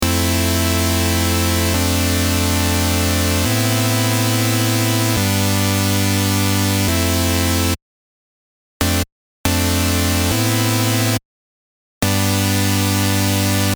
今回はシンセをコードで弾いたフレーズを「Step FX」を使用してON/OFFでリズムを作ってみました。